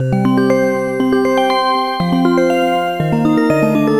Marching Bells.wav